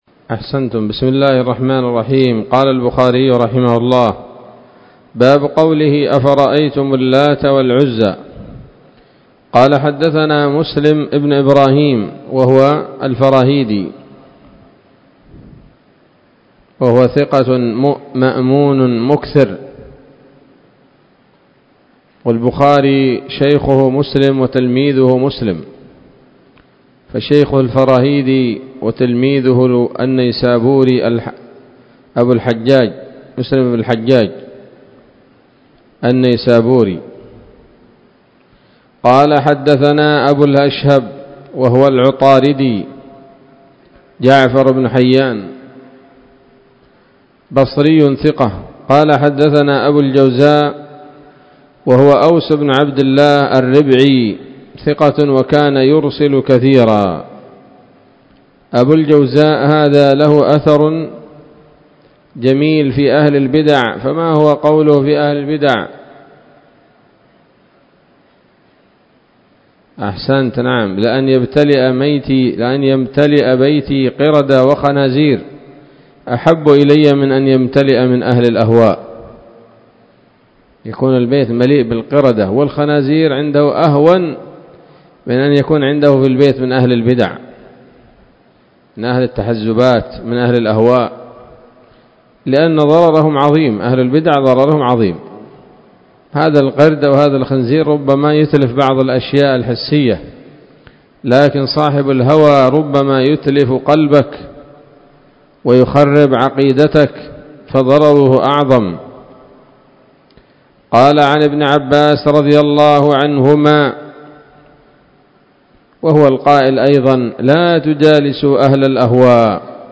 الدرس الثالث والأربعون بعد المائتين من كتاب التفسير من صحيح الإمام البخاري